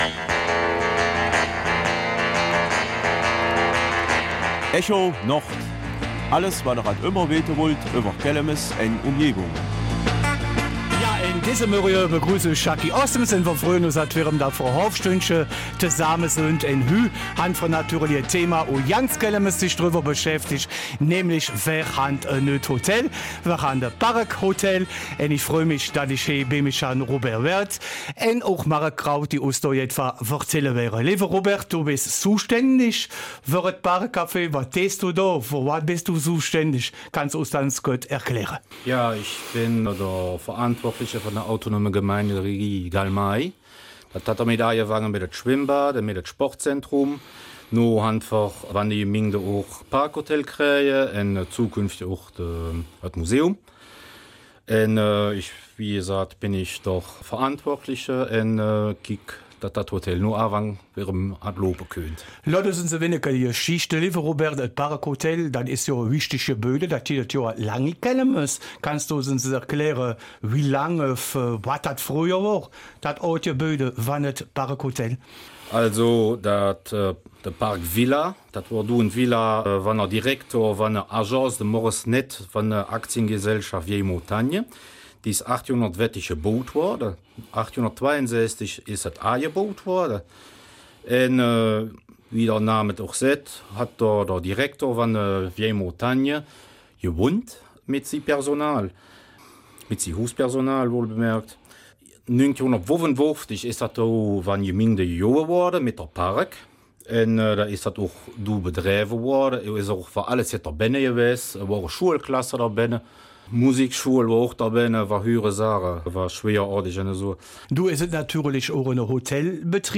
Kelmiser Mundart: Das Park Hotel Kelmis